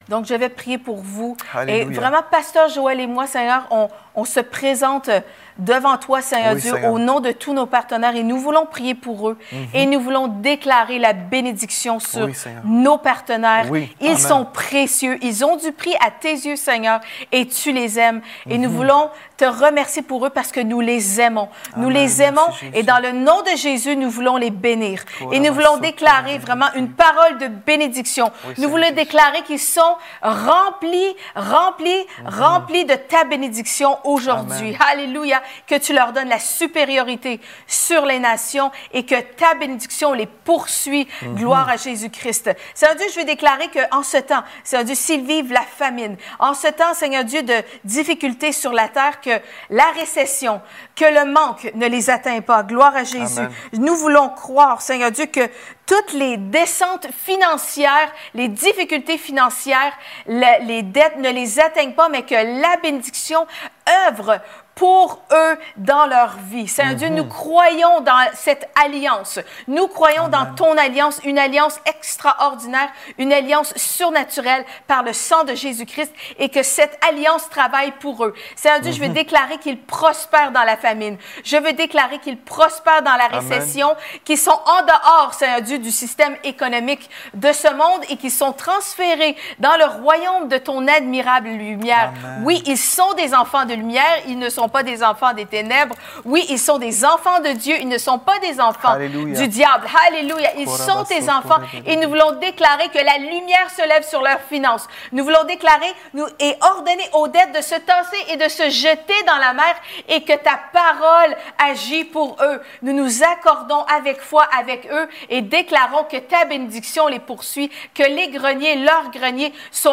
Here is a prayer from our pastors for you:
priere-benediction-donateur.mp3